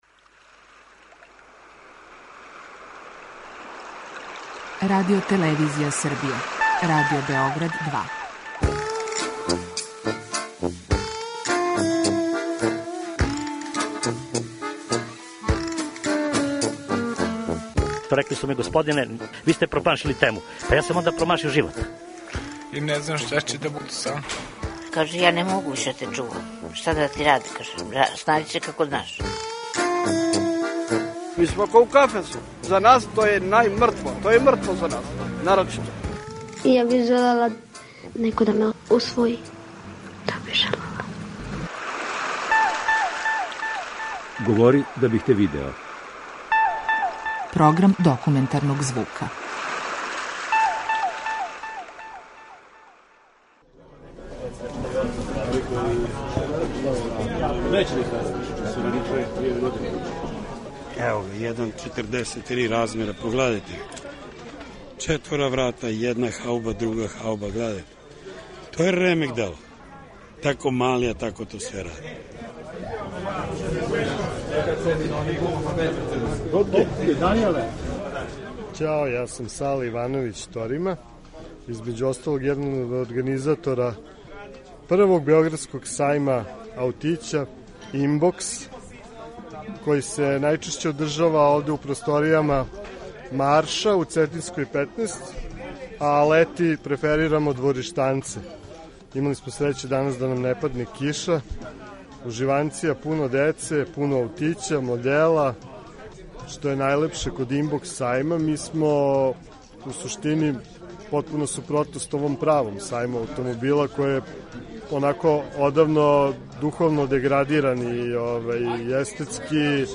Документарни програм
Kолекционари и љубитељи минијатирних аутомобила повремено се окупљају на такозваним сајмовима аутића где размењују своја искуства и употпуњују своје колекције, а посетиоци имају прилике да погледају и купе најразличитије моделе аутомобилчића, који до најситнијих детаља имитирају „праве" аутомобиле. О аутићарству као хобију и страсти за наш програм говорили су ентузијасти и маштари окупљени на једном сајму аутића.